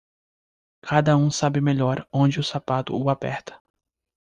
Uitgespreek as (IPA) /saˈpa.tu/